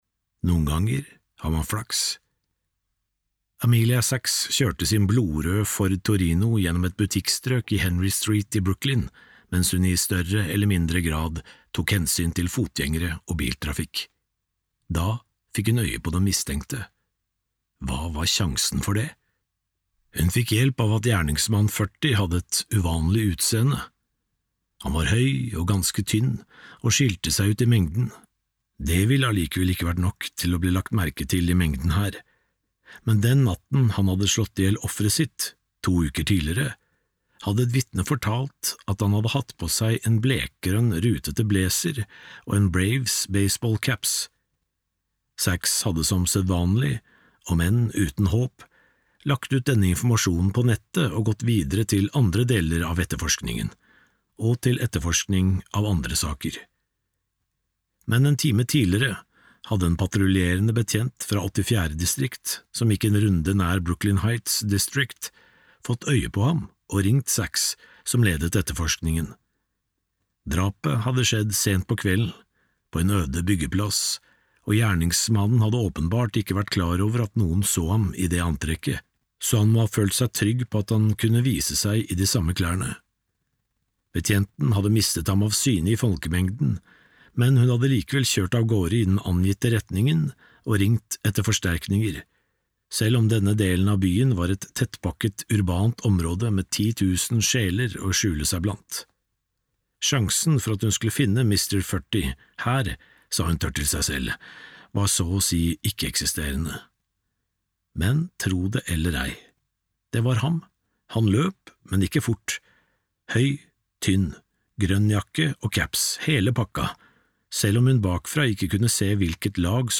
Et kyss av stål (lydbok) av Jeffery Deaver